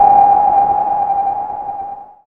SI2 ECHOLOT.wav